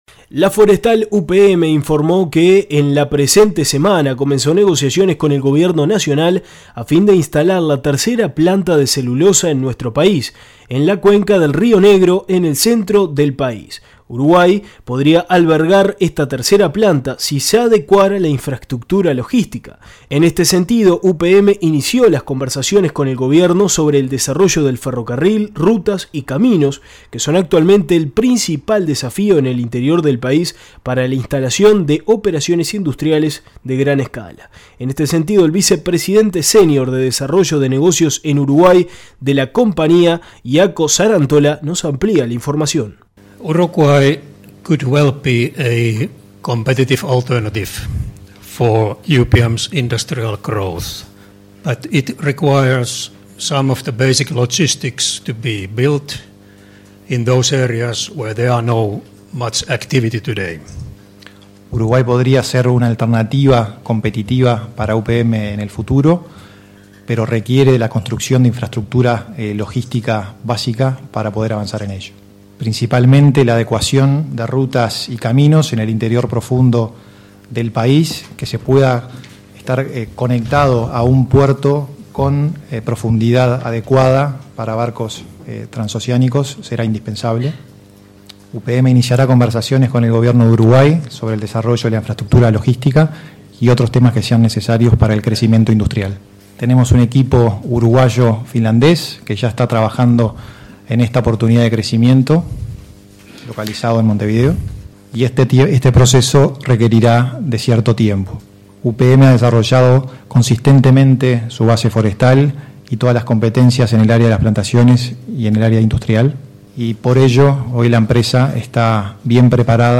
Desde hace algunos años dado las inversiones de capitales extranjeros, el sector forestal está llamado a liderar el ranking de exportaciones de nuestro país, dónde en el último año reportó a la economía local más de 1400 millones de dólares. Es por ello que la forestal UPM, este jueves en conferencia de prensa informó que comenzó negociaciones con el Gobierno de Tabaré Vázquez a fin de instalar la tercera planta de celulosa en Uruguay en la cuenca del río Negro, en el centro del país.